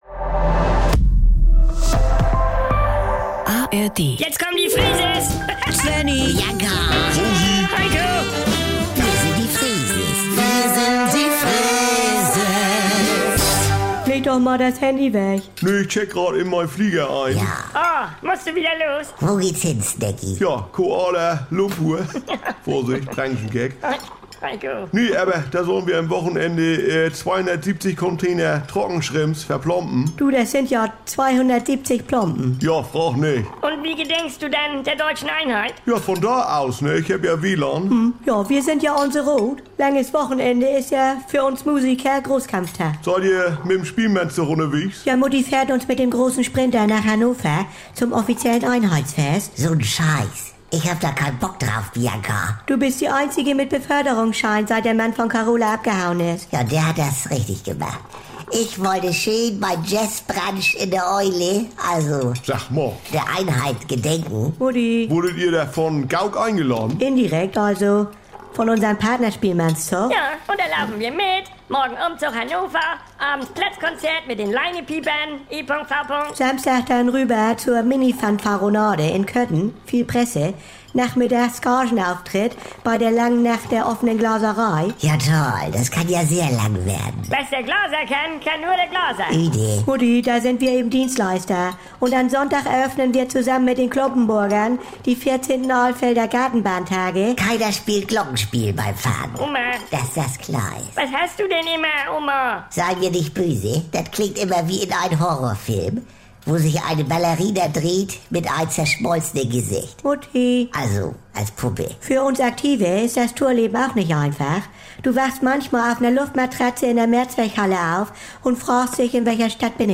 Saubere Komödien Unterhaltung NDR 2 Komödie NDR Freeses Comedy
Hier gibt's täglich die aktuelle Freeses-Folge, direkt aus dem Mehrgenerationen-Haushalt der Familie Freese mit der lasziv-zupackenden Oma Rosi, Helikopter-Mama Bianca, dem inselbegabten Svenni sowie Untermieter und Labertasche Heiko. Alltagsbewältigung rustikal-norddeutsch...